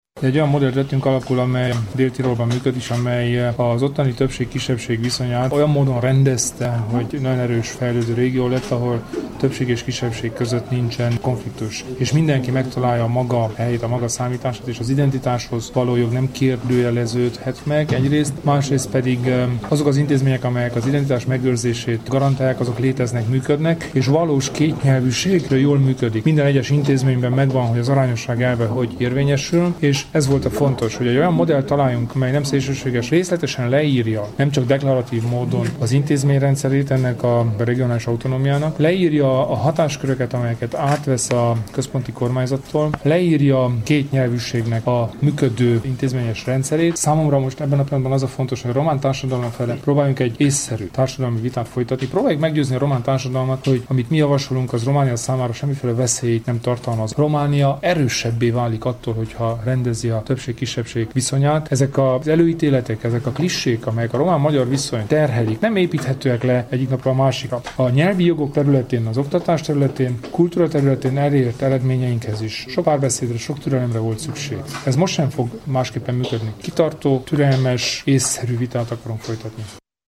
Kelemen Hunor nyilatkozata az autonómia-statútumról meghallgatható itt.